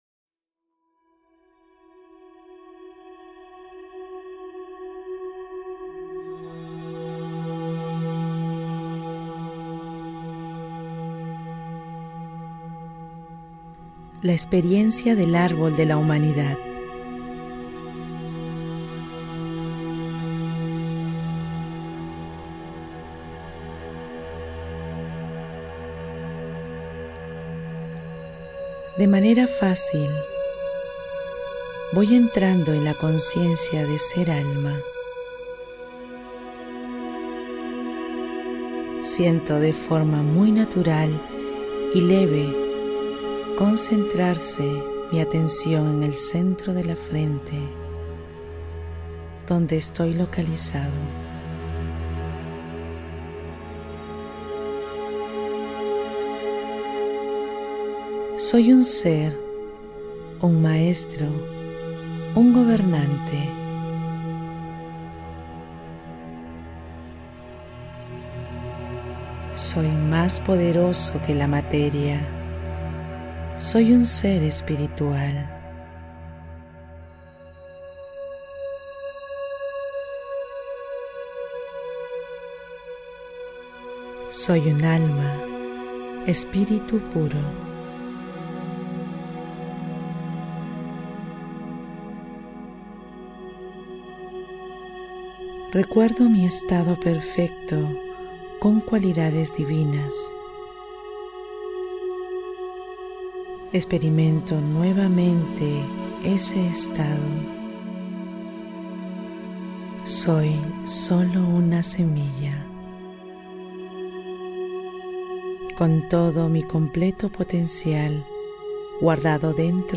meditaciones-guiadas